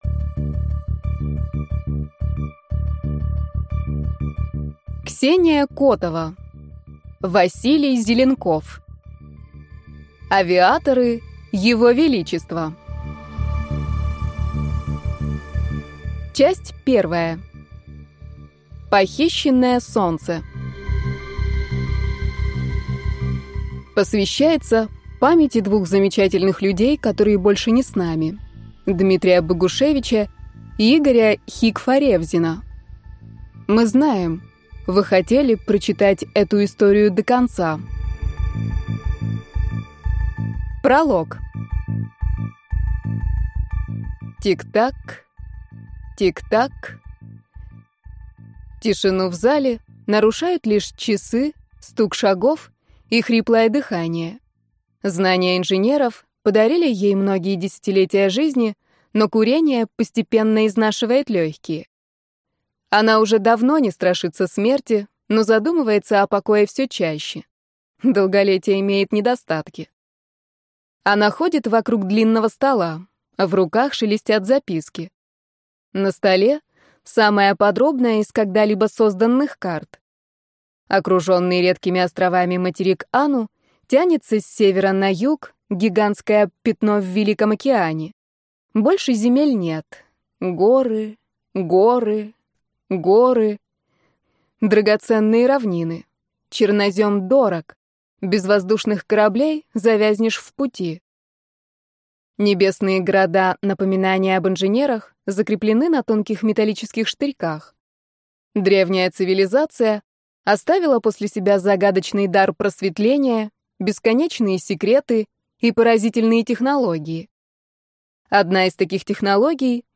Аудиокнига Авиаторы Его Величества | Библиотека аудиокниг
Прослушать и бесплатно скачать фрагмент аудиокниги